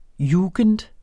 Udtale [ ˈjuːgənd ]